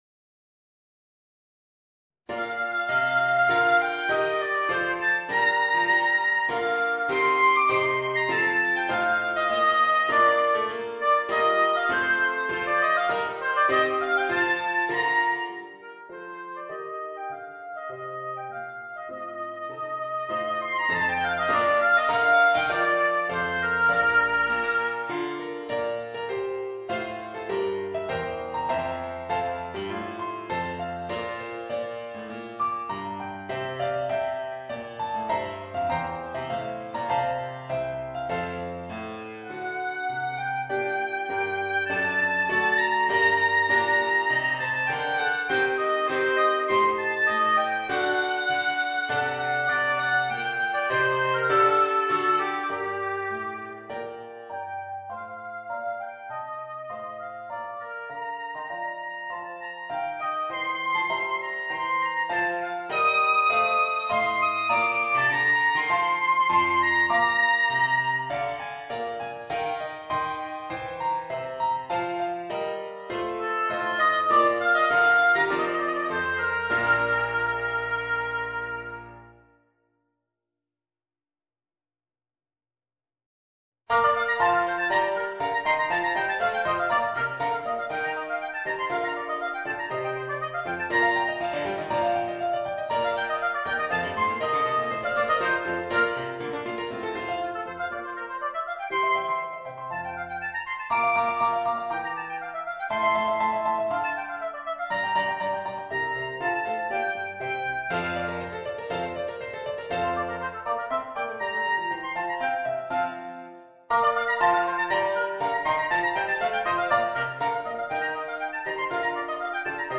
Voicing: Oboe and Organ